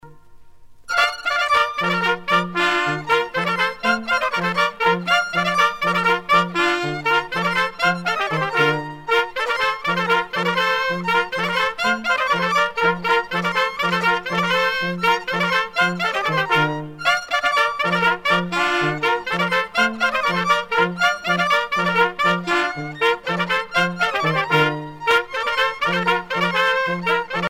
danse : angoise, maristingo
groupe folklorique
Pièce musicale éditée